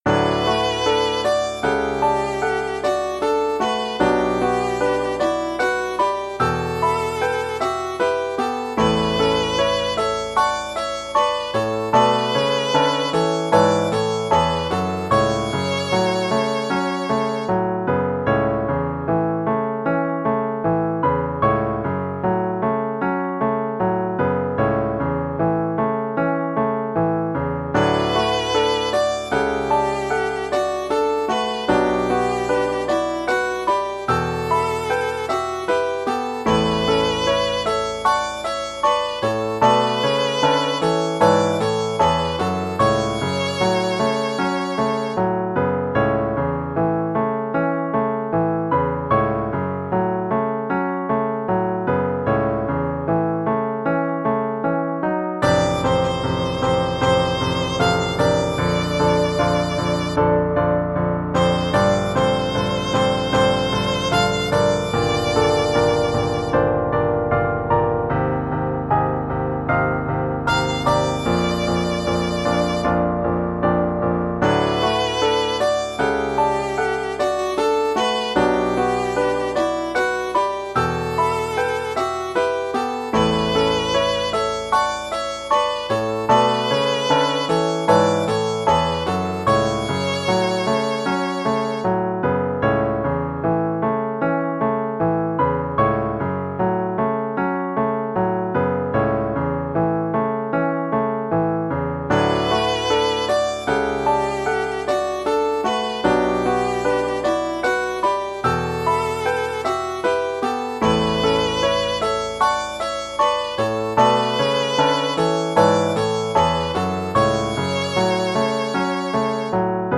The MP3s below are exported from the MIDI.